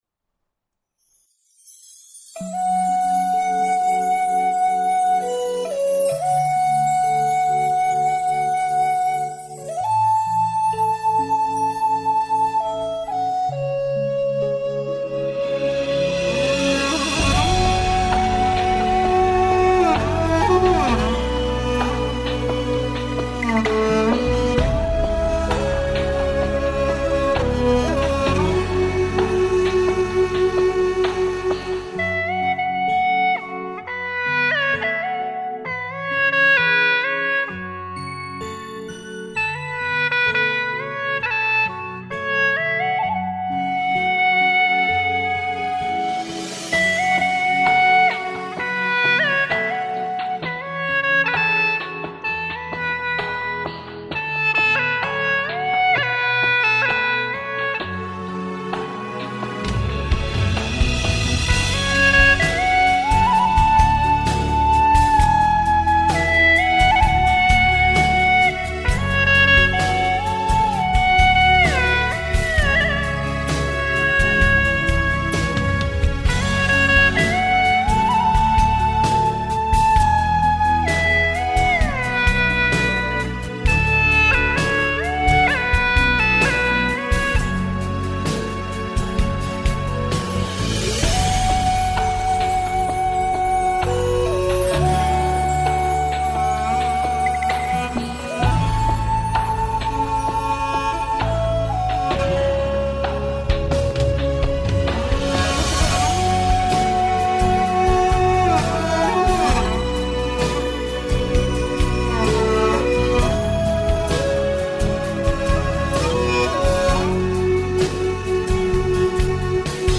调式 : D 曲类 : 民族
【大小D调筒音1】